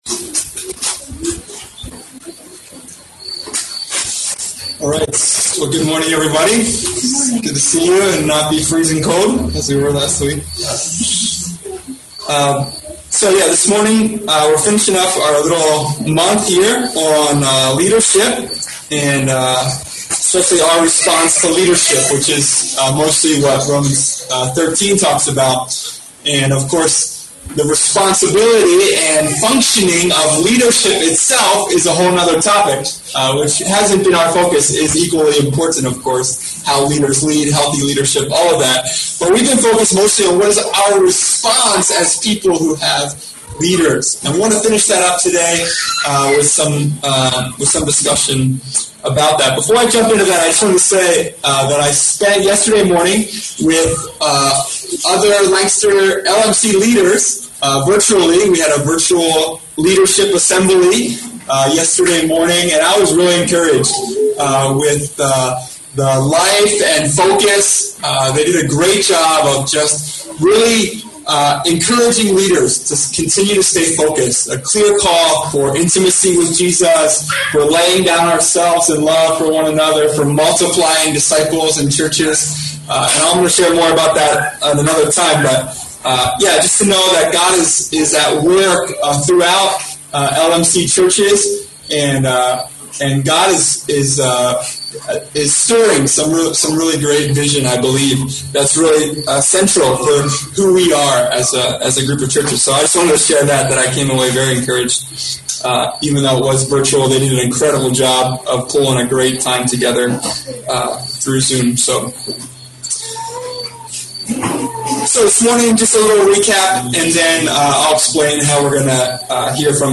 Reviewing the past two weeks, and discussing together the complex questions of how we respond to leadership. (We apologize for the low sound quality as we are holding outdoor services.).